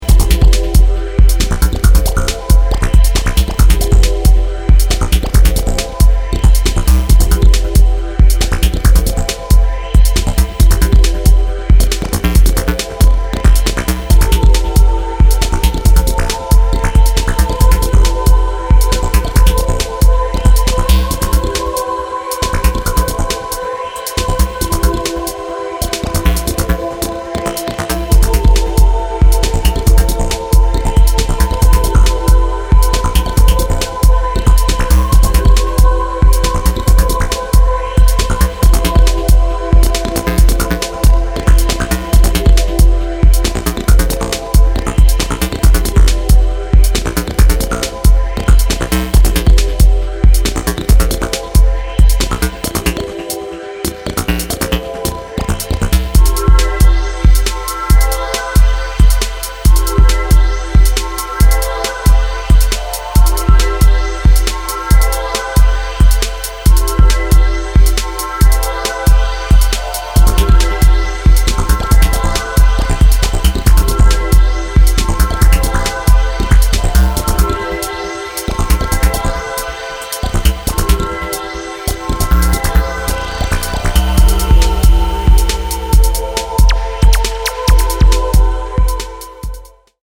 Cut live from The Tunnel